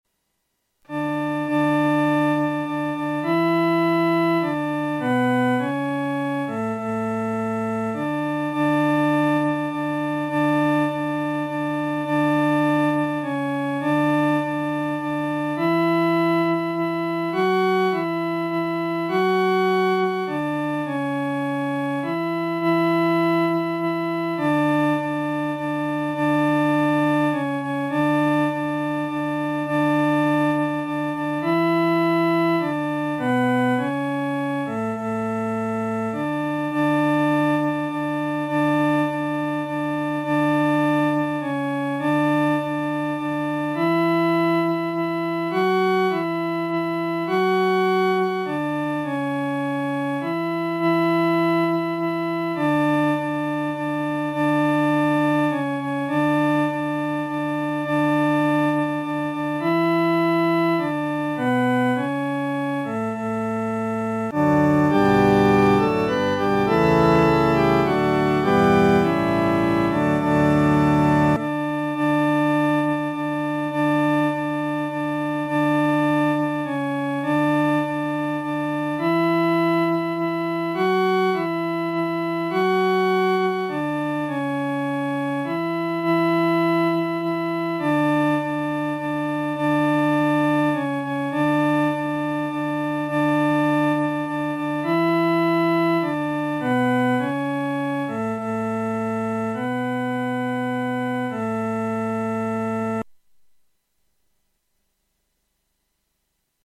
伴奏
女低
这首曲调格式简单，节奏鲜明，感情丰富，旋律多变，如同海浪起伏。